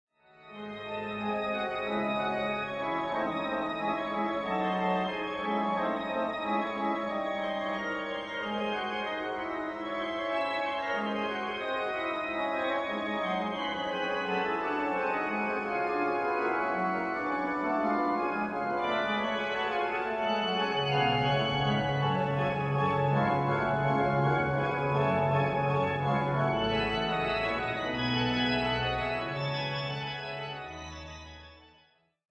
Come la pittura e l'architettura possono esprimersi in forme più complesse, anche la musica può elaborare melodie meno semplici, formate dall'intreccio di più voci melodiche: è il cosiddetto contrappunto, il linguaggio del Cinque-Seicento.
L'ascolto propone un frammento della Fuga in Re minore per organo di Johann Sebastian Bach (1685-1750): bach_fuga.mp3
bach_fuga.mp3